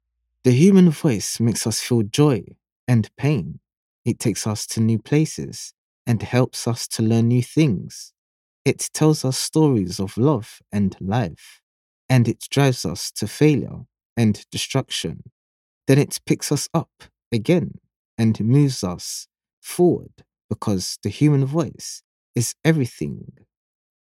An authentic, believable and conversational voice suitable for educational, corporate and commercial projects.